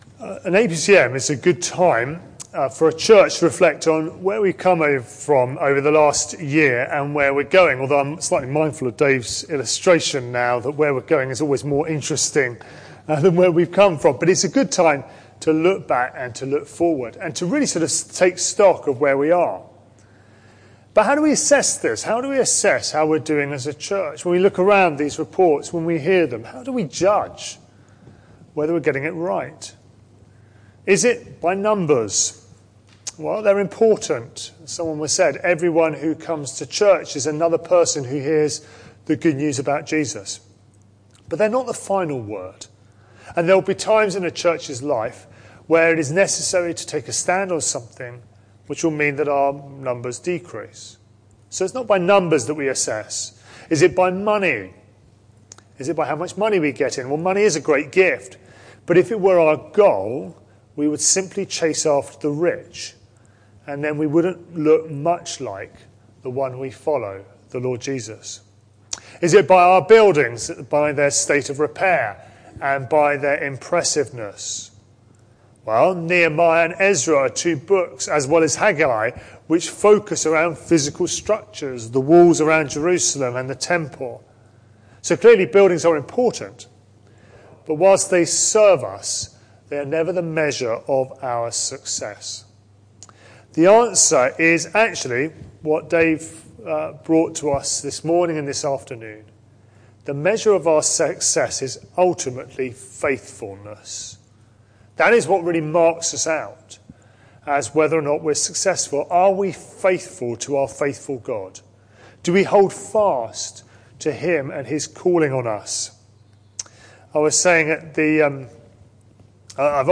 Vicar's APCM Address